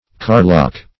Carlock \Car"lock\, n. [F. carlock, fr. Russ.